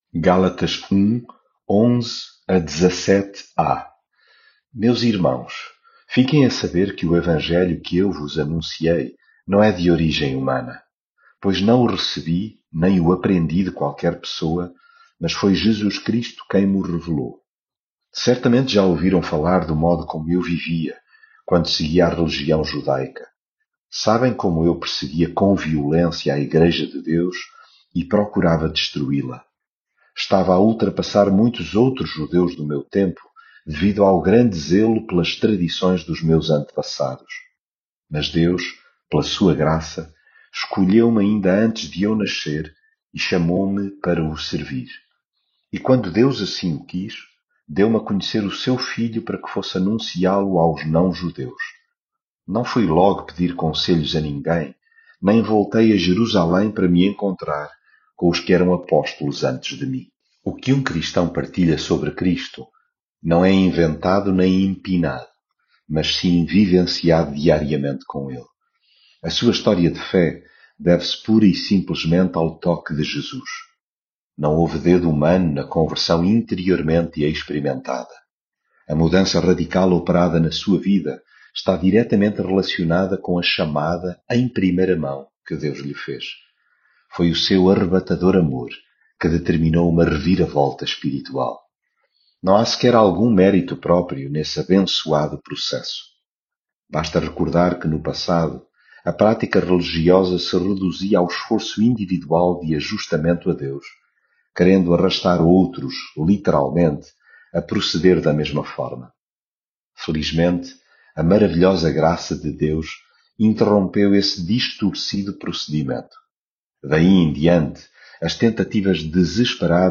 devocional gálatas
leitura bíblica